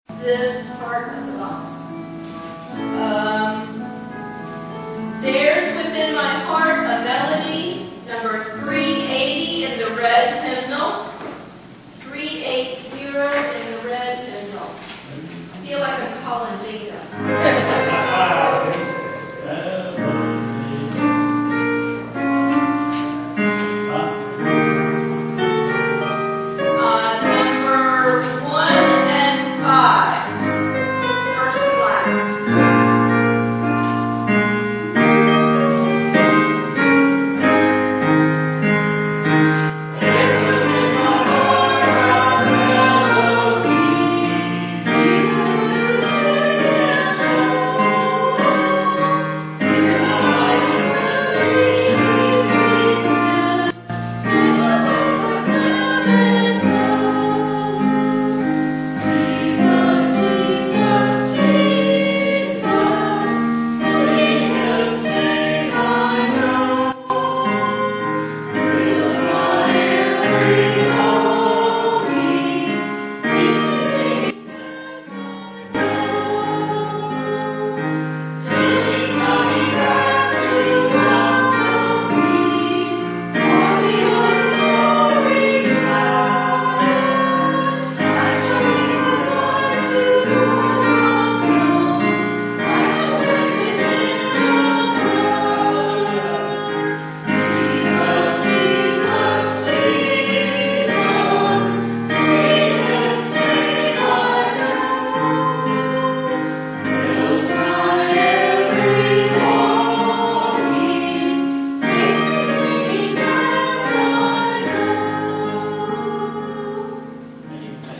Hymn Sing
It has been said that each hymn contains a sermon/message, so consider taking some time to read all of the verses to reveal the full message of the hymn...Each recording includes statements made before the hymn is sung...as well as any comments made after the singing of the preceding hymn.